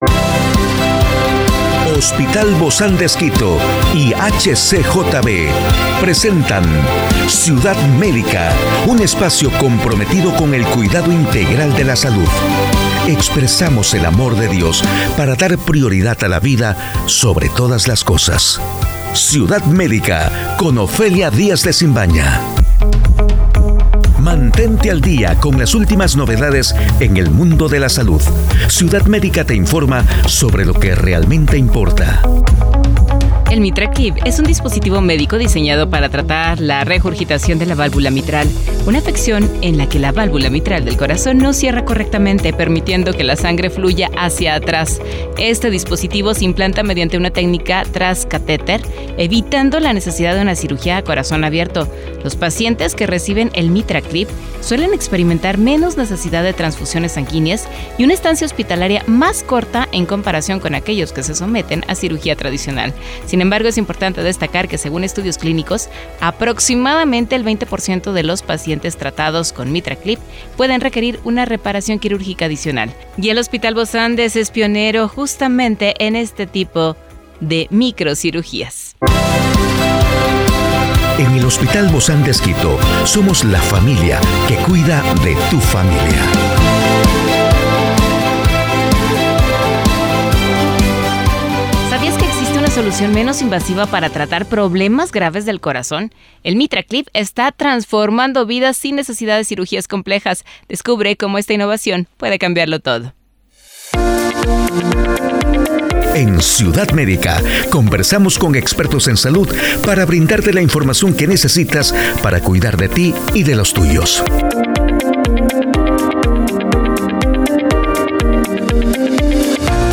Conversemos con nuestros doctores sobre el MItraclip, sus riesgo y sobretodo sus beneficios.